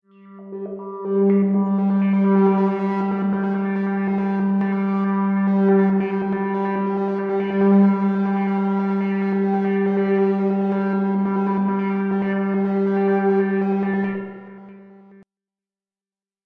描述：通过Modular Sample从模拟合成器采样的单音。
标签： MIDI-速度-64 FSharp5 MIDI音符-79 ELEKTRON-模拟四 合成器 单票据 多重采样
声道立体声